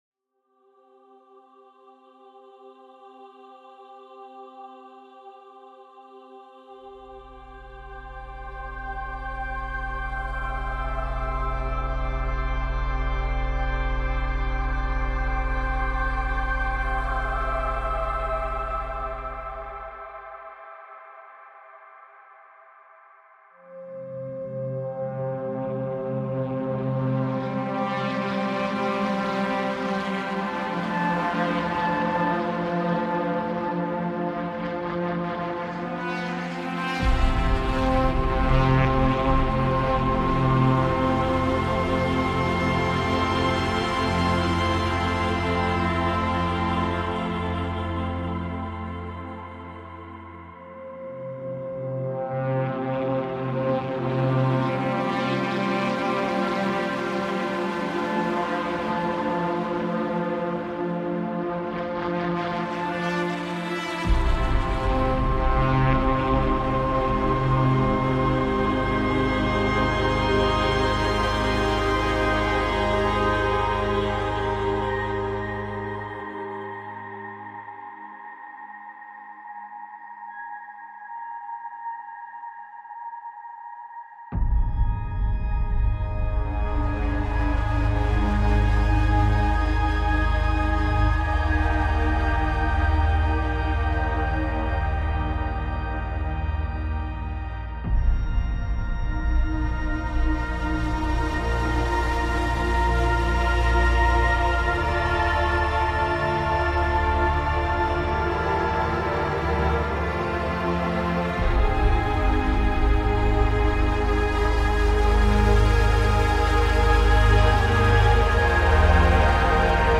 partition spatiale envoûtante